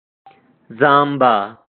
Marwati Pashto for Jawbone